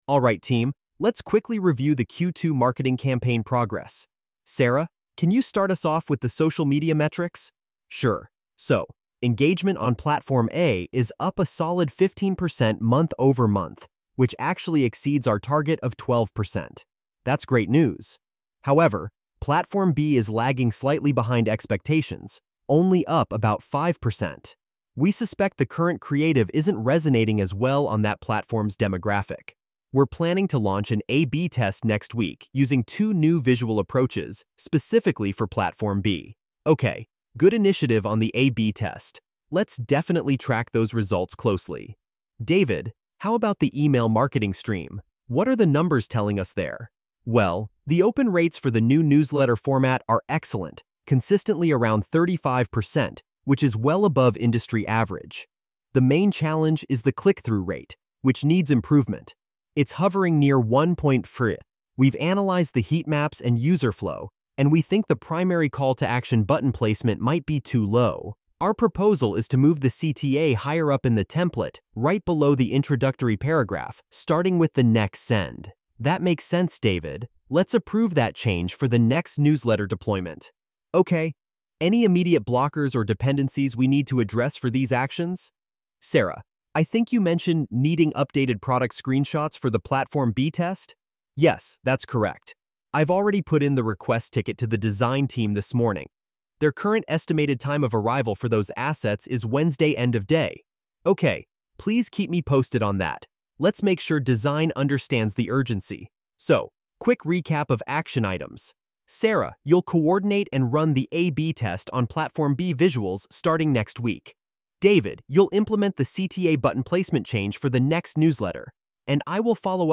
discussion_audio.mp3